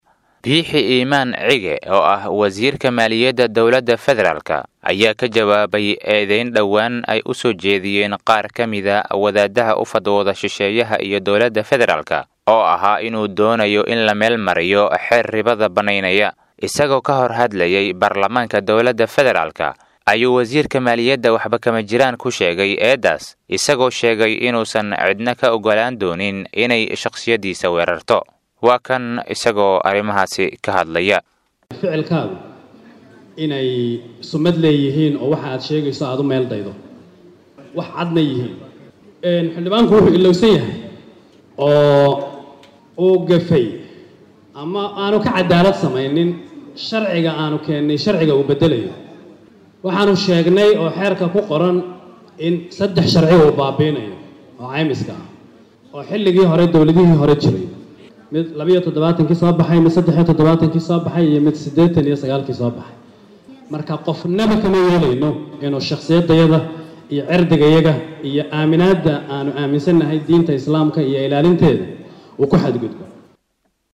Isagoo ka hor hadlayay baarlamaanka Dowladda Federaalka, ayuu wasiirka Maaliyadda waxba kama jiraan ku sheegay eedaas, isagoo sheegay inuusan cidna ka ogolaan doonin iney shakhsiyadiisa weerarto.